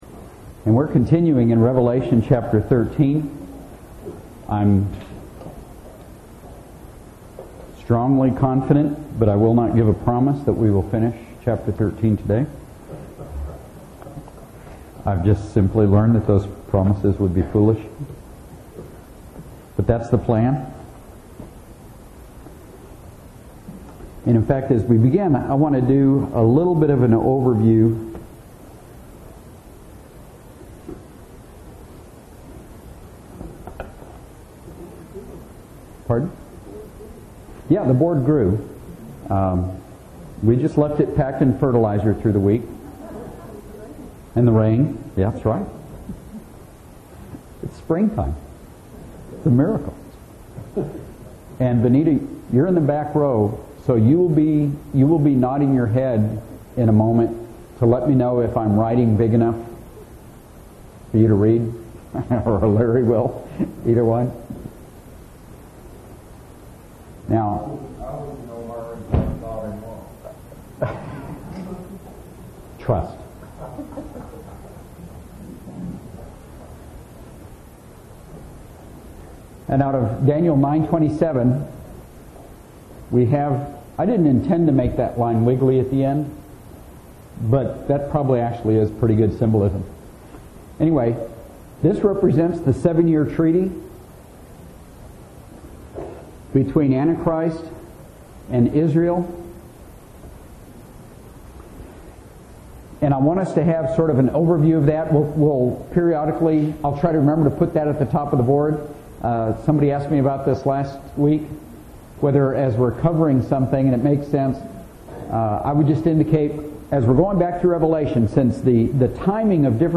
Grace Bible Fellowship, Bastrop Texas
Sermons